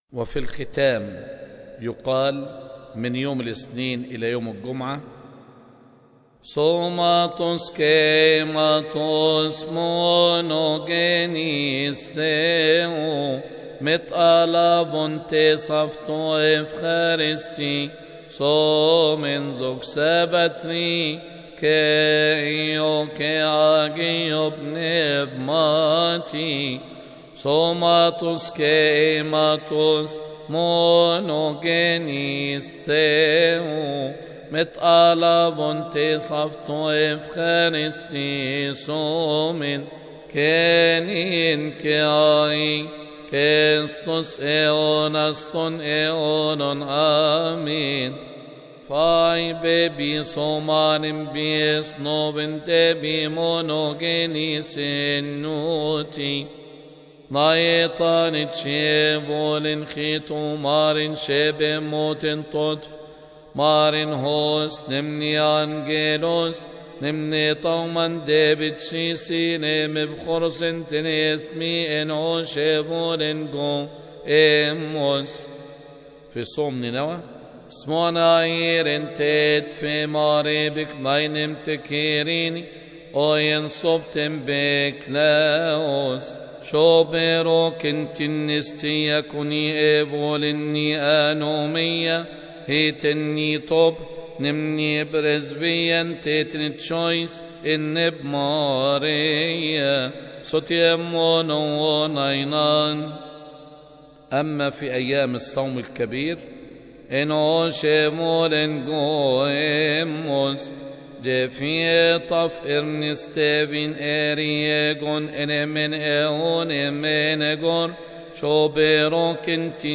استماع وتحميل لحن سوماتوس ختام أيام الصوم الكبير من مناسبة som-kebir